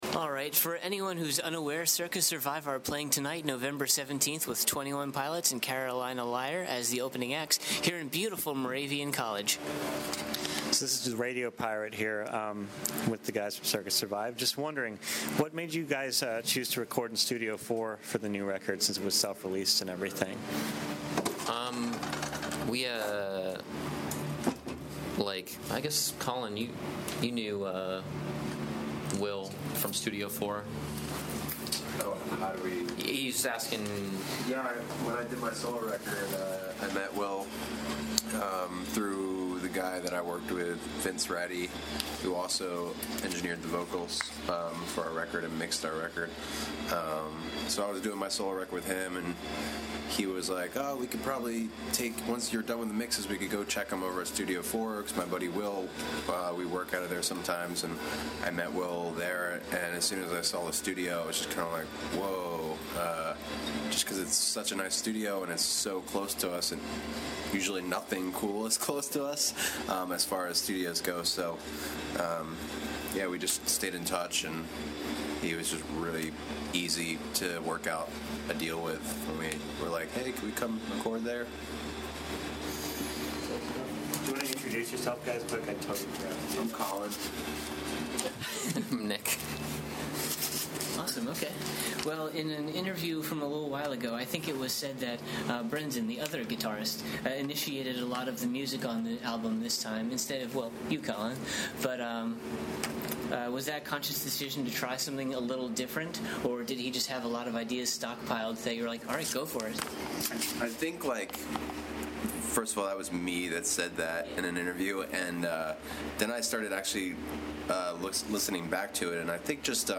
26-interview-circa-survive.mp3